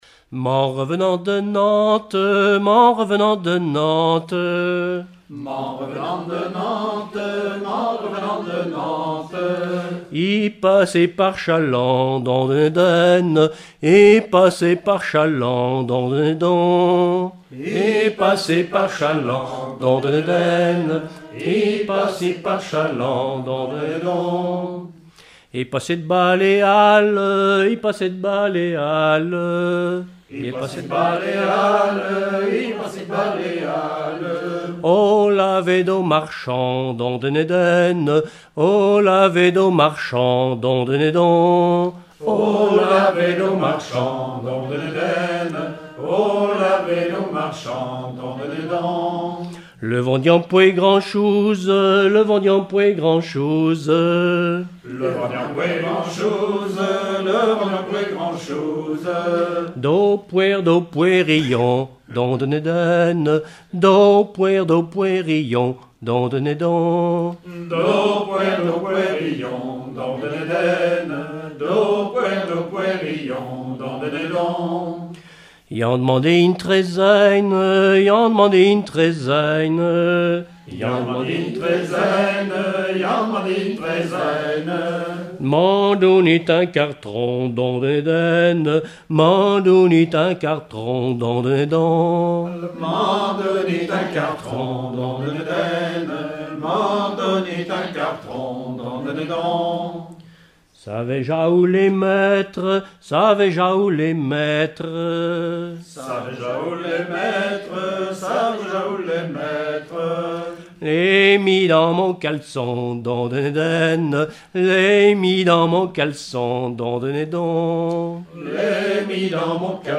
enregistrement d'un collectif lors d'un regroupement cantonal
Pièce musicale inédite